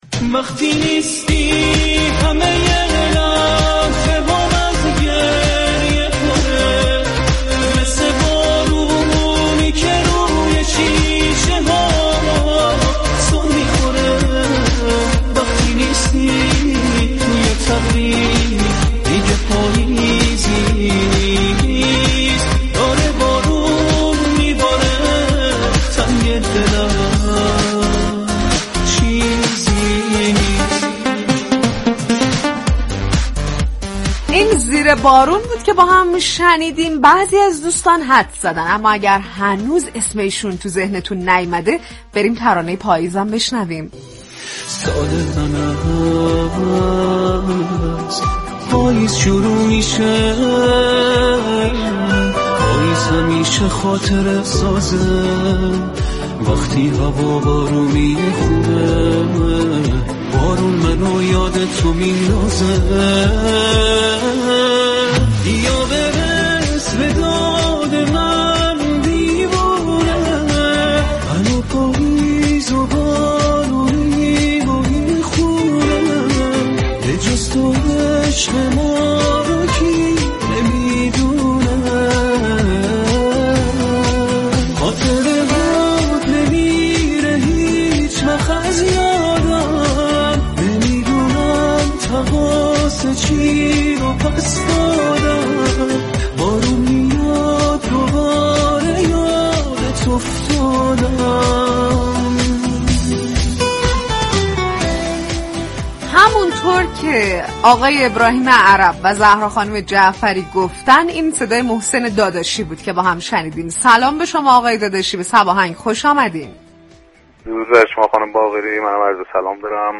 محسن داداشی خواننده خوب كشورمان در بخش گفتگوی ویژه مهمان برنامه «صباهنگ» رادیو صبا شد